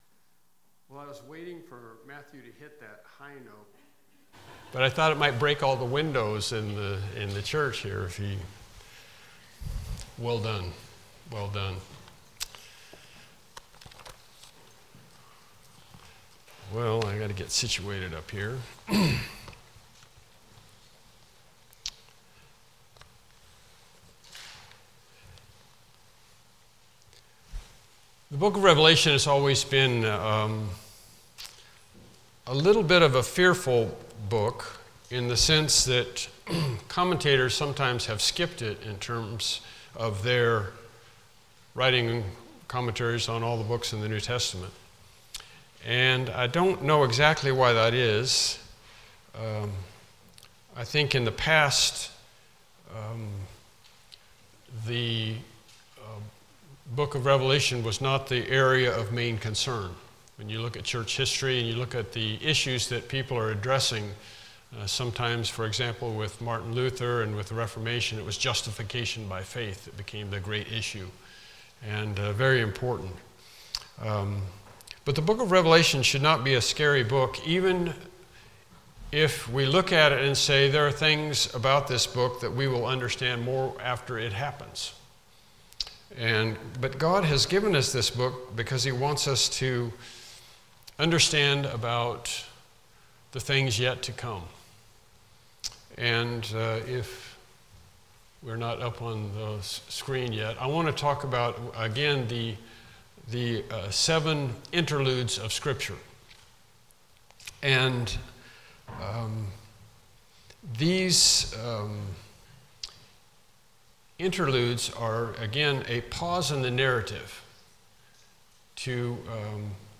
Evening Sermons Passage: Revelation 12 Service Type: Evening Worship Service « Faith and the Holy Spirit Lesson 11